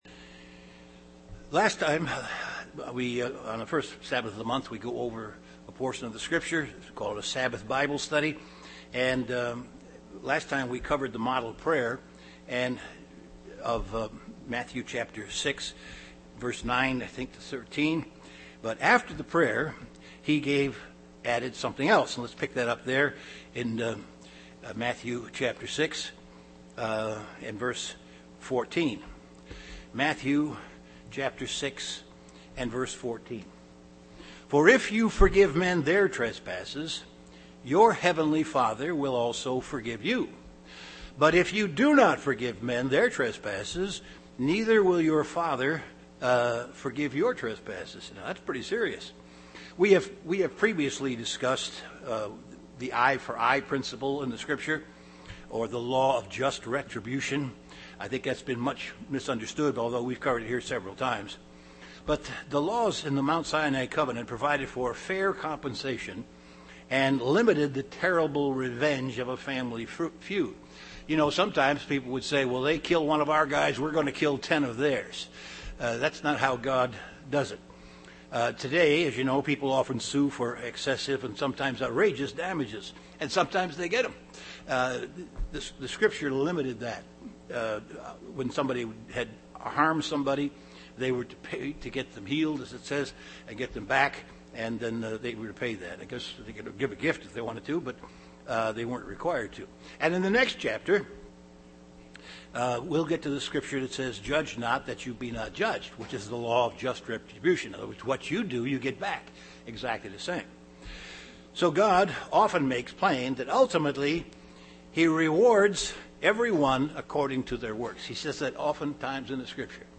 Continuation of an in-depth Bible study on the Sermon on the Mount.
Given in Chicago, IL Beloit, WI
UCG Sermon Sermon on the Mount Studying the bible?